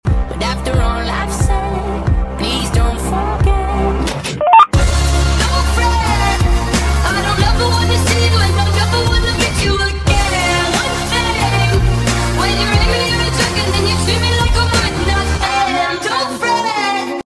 Who doesn’t love tne greenstar noise